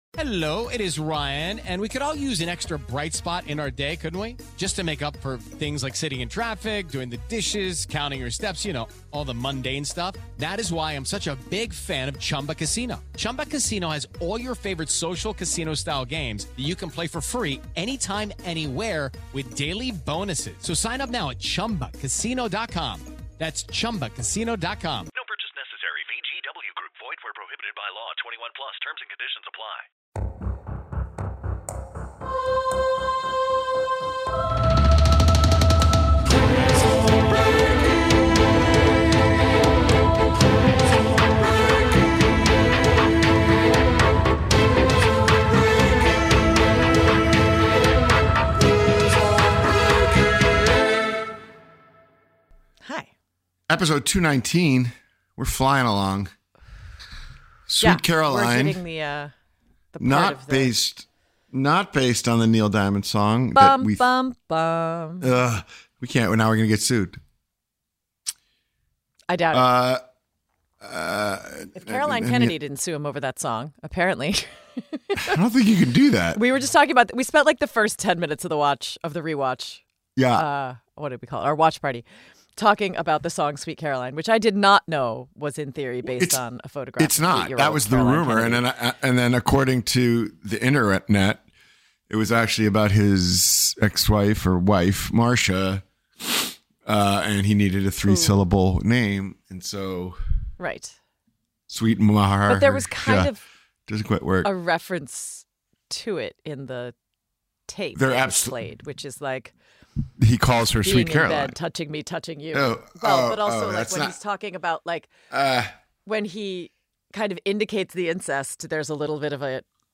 In this episode, hosts Sarah Wayne Callies and Paul Adelstein discuss 'Prison Break' Season 2, Episode 19, titled 'Sweet Caroline.' They dive into the episode’s significant plot developments, including a surprising twist involving the President, revealing an incestuous relationship that led to blackmail and resignation.